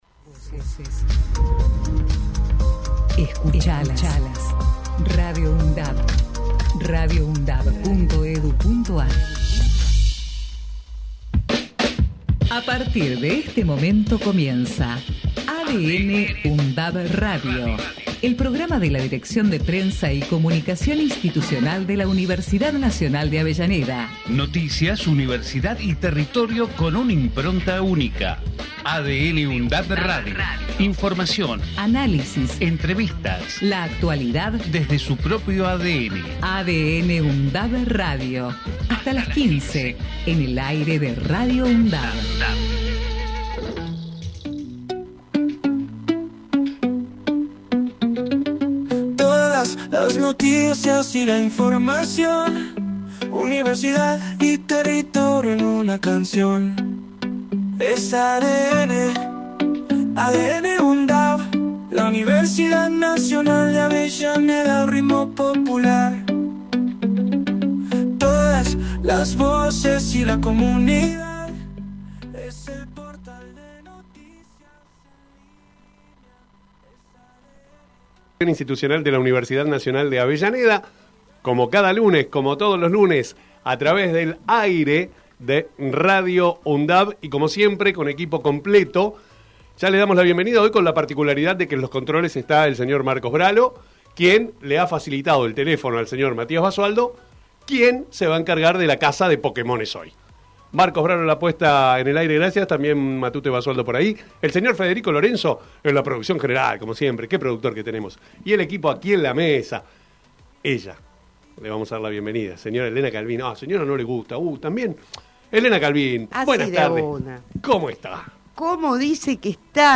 ADN | UNDAV – Radio. Texto de la nota: El programa de la Dirección de Prensa y Comunicación Institucional de la Universidad Nacional de Avellaneda en su emisora Radio UNDAV, busca transmitir la impronta de la Universidad, su identidad, su ADN de una forma actual y descontracturada, con rigurosidad y calidad informativa. Noticias, universidad y territorio son los tres ejes que amalgaman la nueva propuesta a través de la imbricación y la interrelación de las temáticas que ocupan y preocupan a la comunidad local, zonal y nacional desde una mirada universitaria, crítica y constructiva a través de voces destacadas del mundo académico, político, cultural y social.